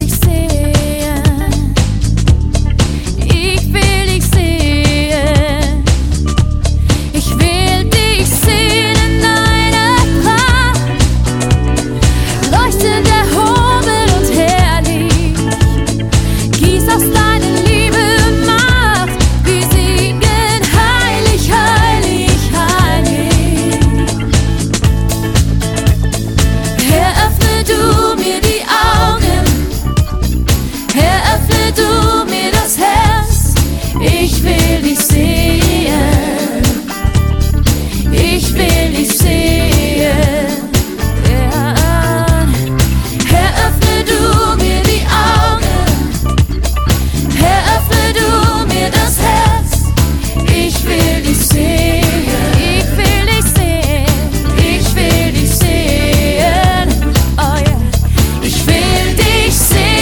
Worship 0,99 €